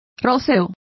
Complete with pronunciation of the translation of roseate.